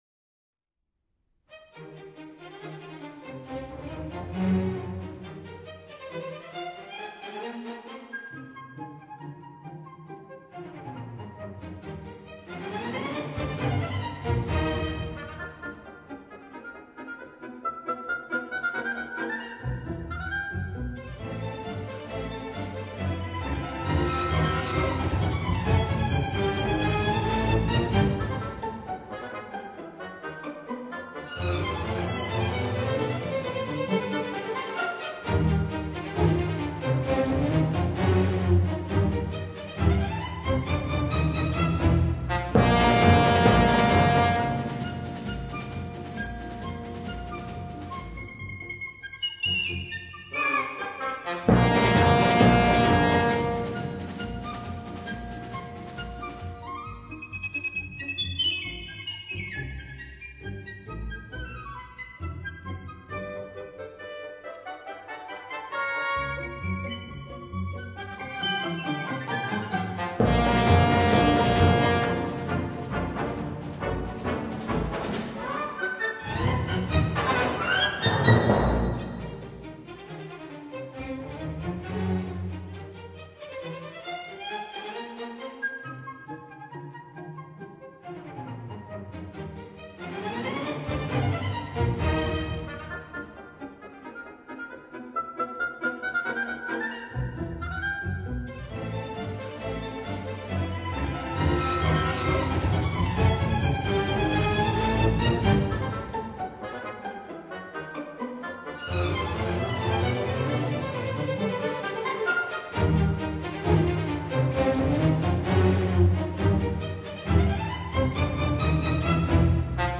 分辑：CD1-CD11 交响曲全集